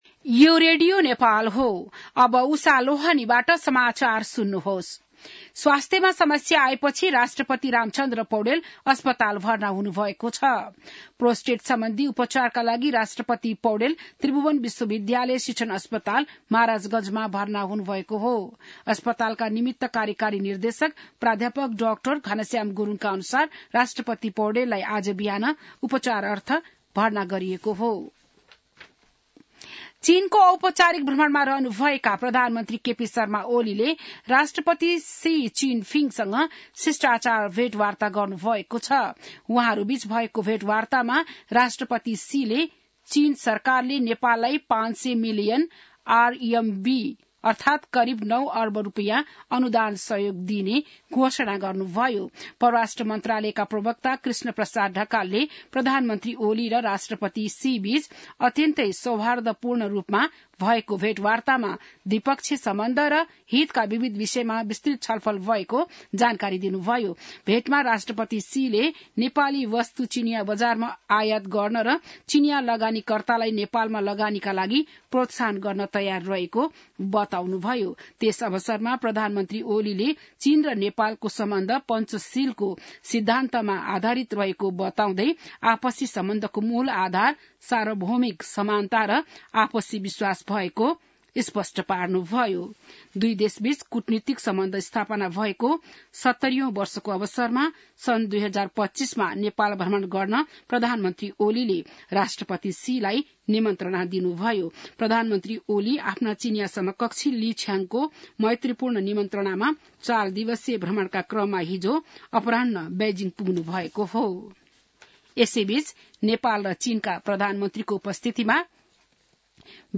बिहान १० बजेको नेपाली समाचार : २० मंसिर , २०८१